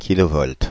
Ääntäminen
Ääntäminen France (Île-de-France): IPA: /ki.lo.vɔlt/ Haettu sana löytyi näillä lähdekielillä: ranska Käännöksiä ei löytynyt valitulle kohdekielelle.